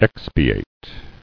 [ex·pi·ate]